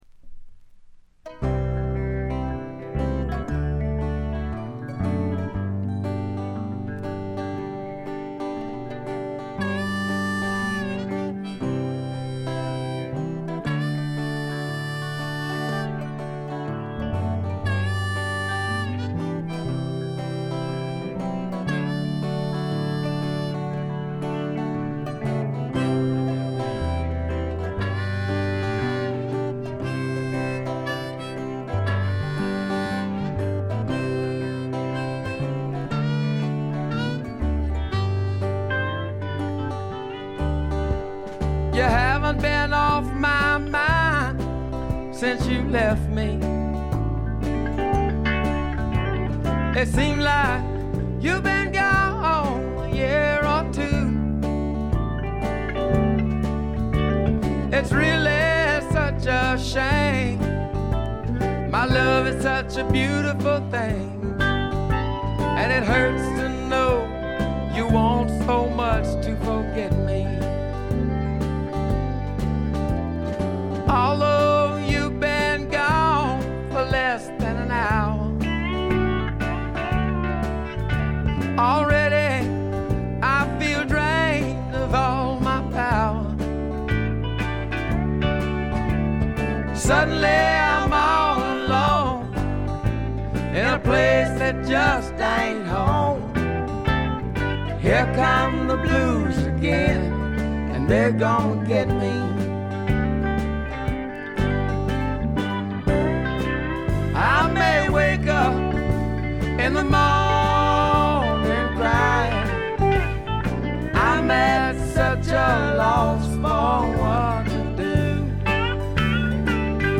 部分試聴ですがほとんどノイズ感無し。
びしっと決まった硬派なスワンプ・ロックを聴かせます。
試聴曲は現品からの取り込み音源です。
Recorded at Paramount Recording Studio.